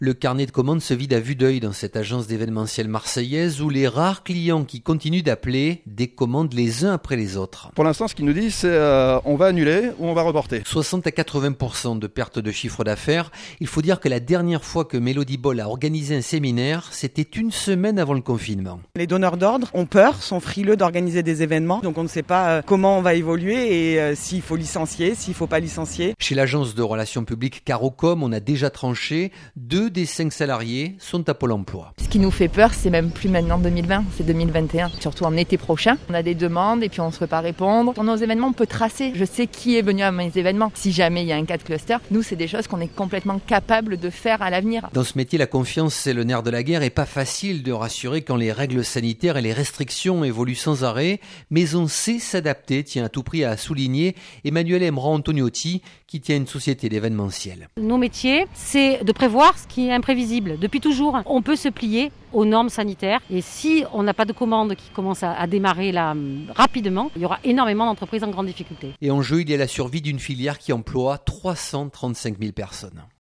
Sur le Vieux Port à Marseille : 300 professionnels de l'événementiel enchaînés les uns aux autres.
Reportage